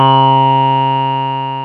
HOHNER 1982 3.wav